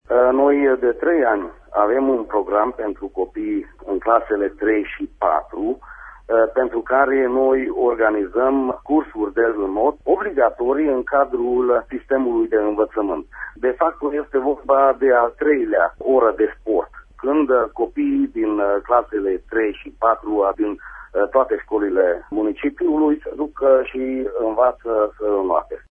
Detalii ne oferă viceprimarul municipiului Miercurea Ciuc – Fuleki Zoltan:
stiri-28-sept-fuleki-inot.mp3